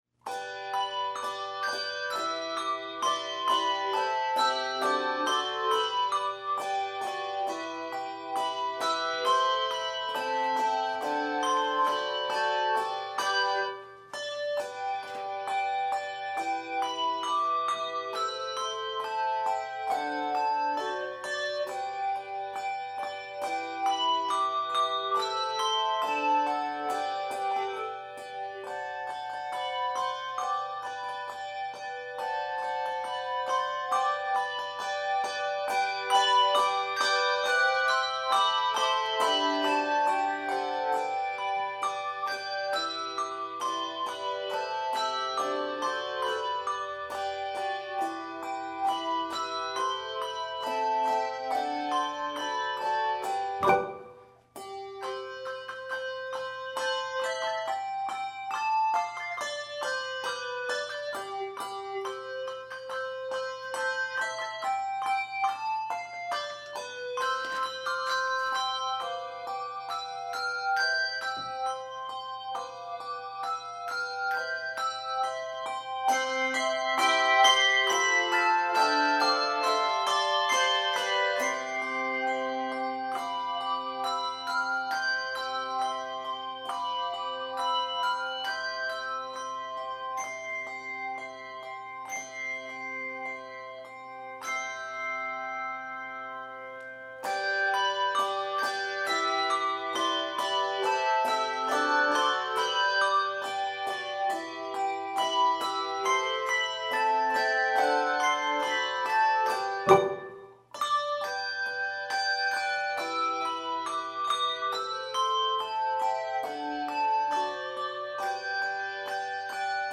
Voicing: Handbells 2-3 Octave